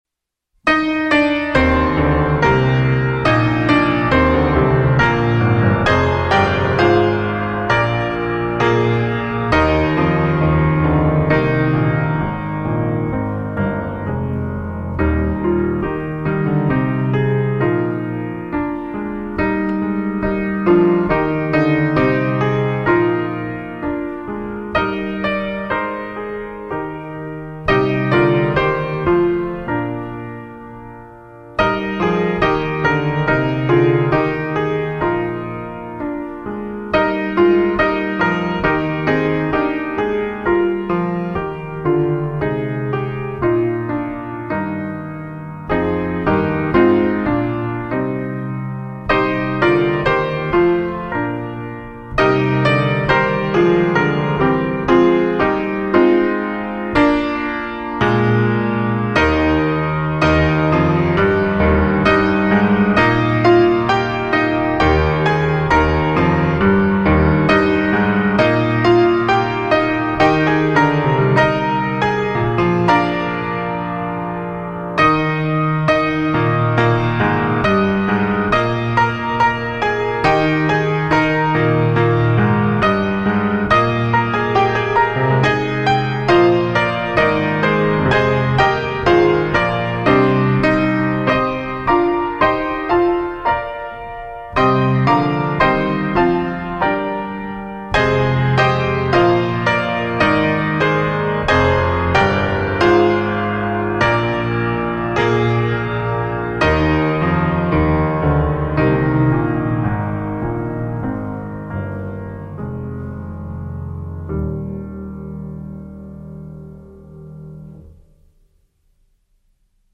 PIANO SOLO Sacred Hymn Favorite, Piano Solo
DIGITAL SHEET MUSIC - PIANO SOLO